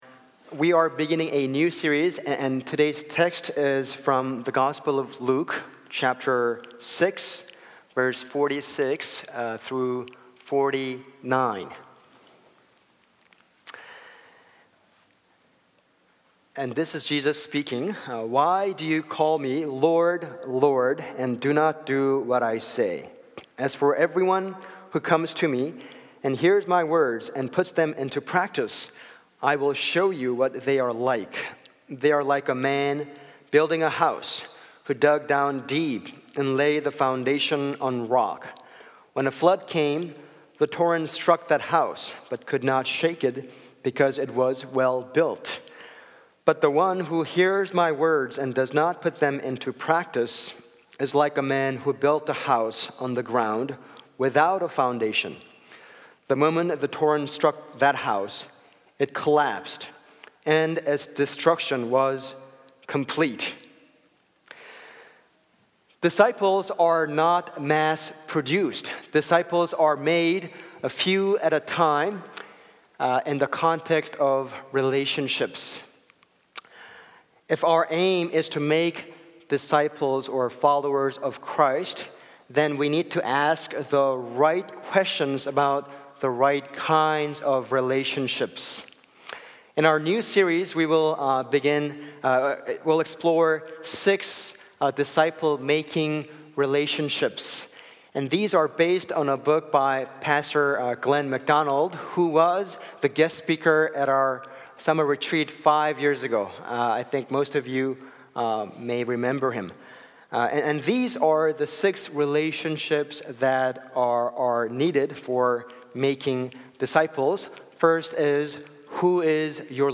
Sunday-sermon-14.mp3